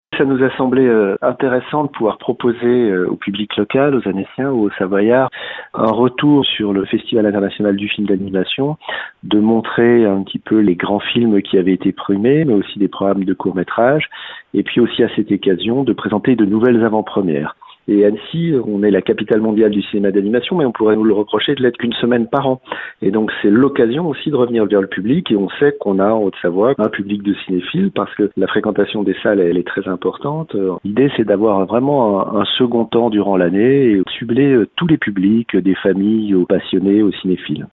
Dominique Puthod dévoile l’idée qui a motivé cette création.
Il est maire délégué d’Annecy et président du Festival international du film.